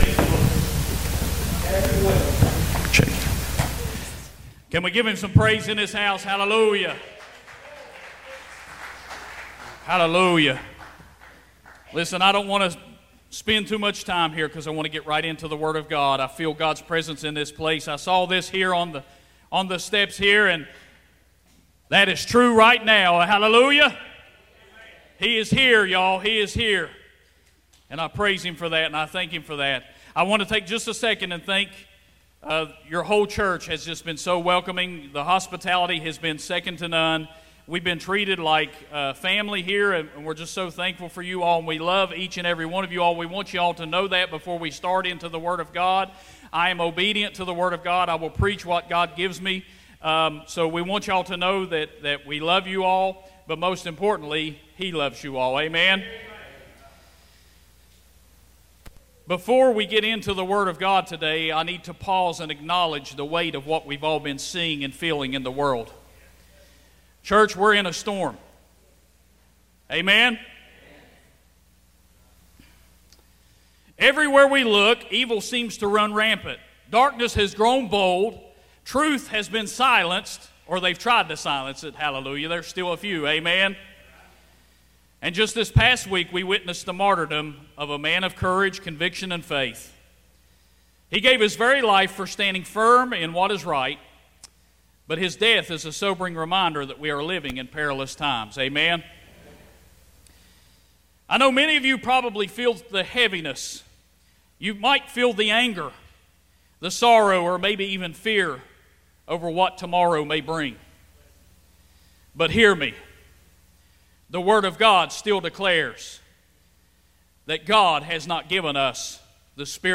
Sunday Morning Worship Revival